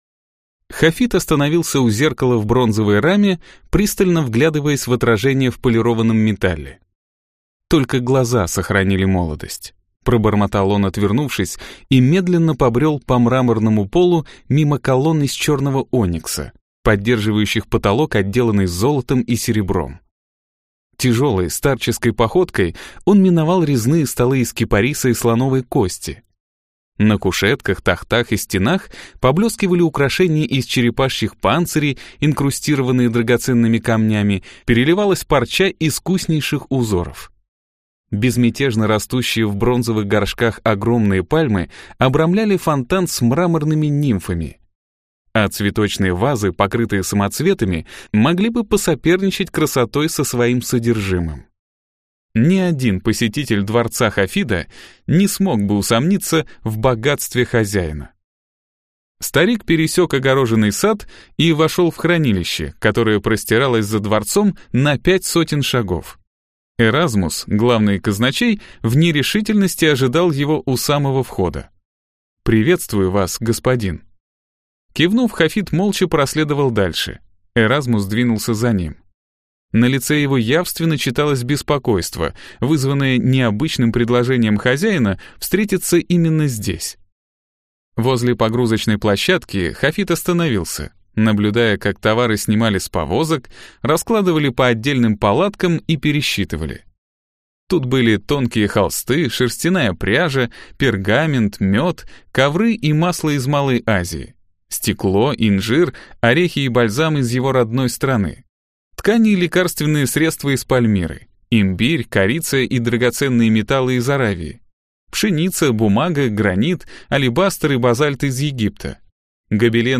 Аудиокнига Самый великий торговец в мире | Библиотека аудиокниг